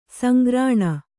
♪ sangrāṇa